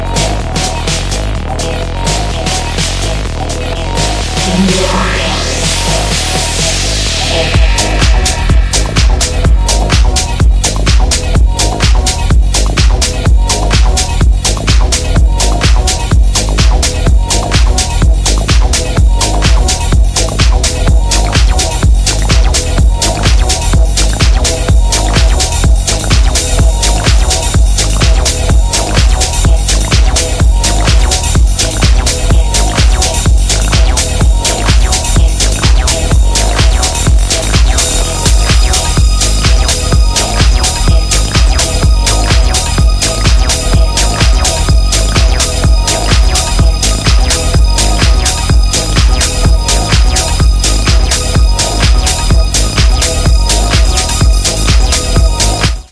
Acid House / Tech House / Electro